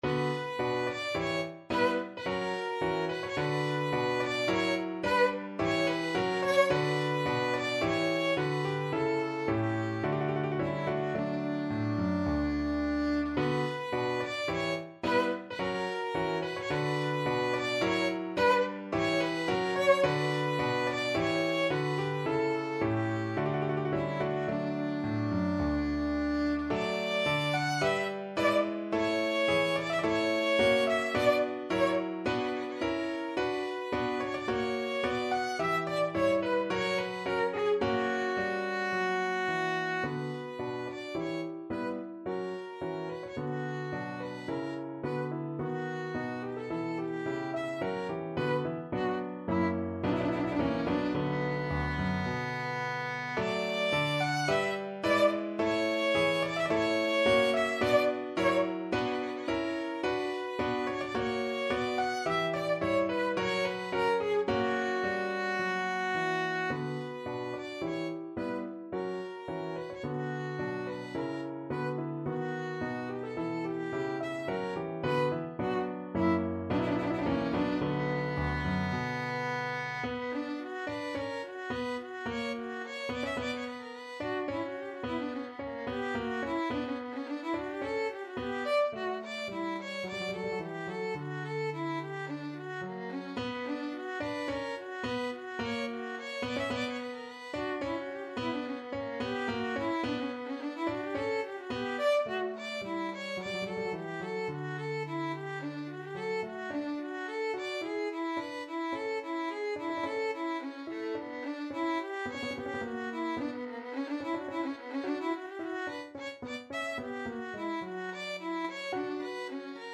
3/4 (View more 3/4 Music)
~ = 54 Moderato
Classical (View more Classical Violin Music)